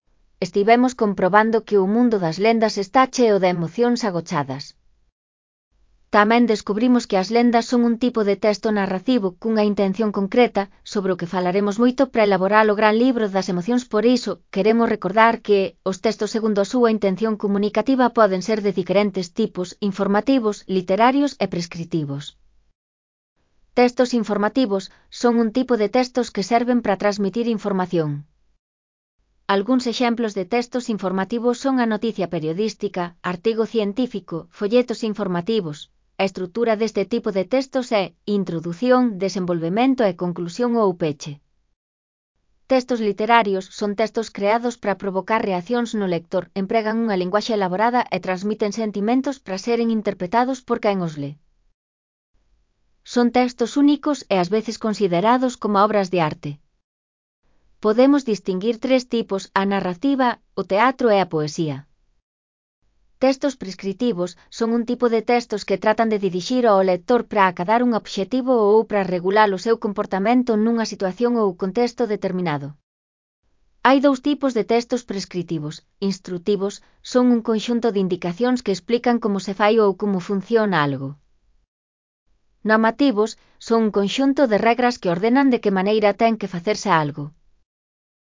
Elaboración propia (Proxecto cREAgal) con apoio de IA, voz sintética xerada co modelo Celtia. . Textos segundo a súa intención comunicativa (CC BY-NC-SA)